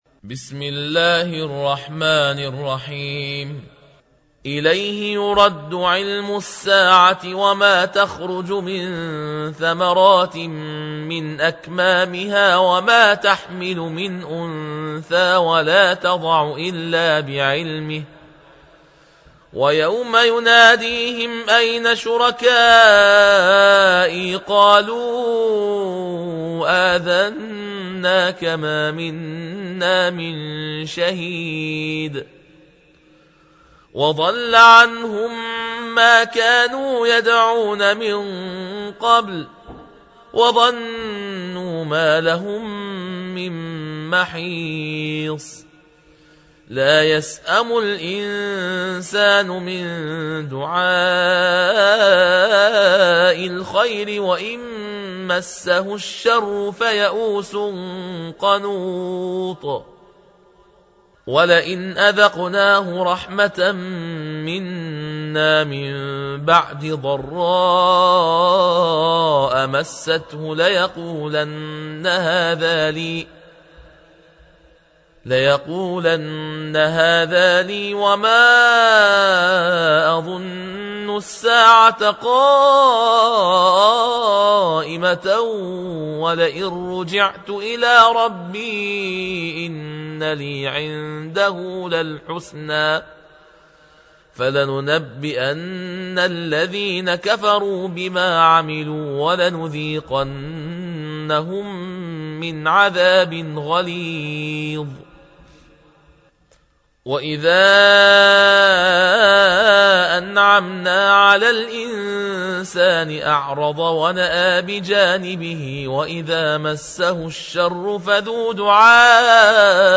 الجزء الخامس والعشرون / القارئ